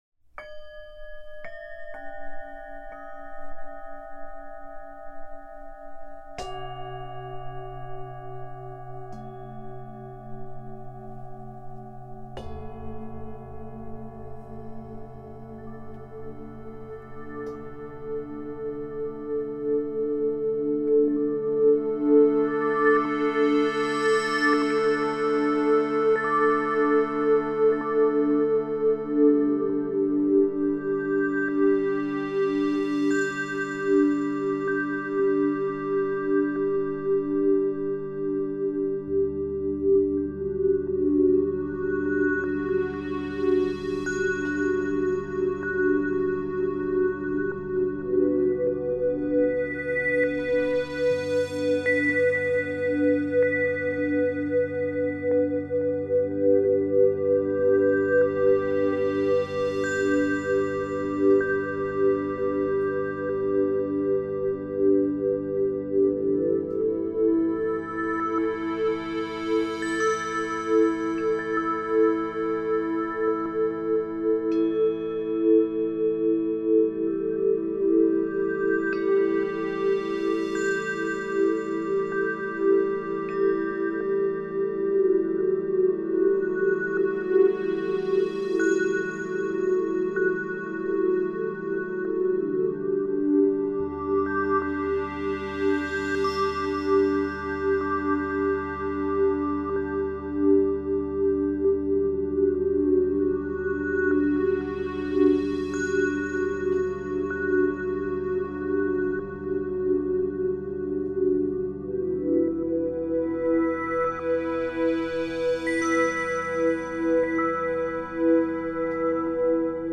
Sottofondo di Meditazione
sottofondo-meditazione-lungo.mp3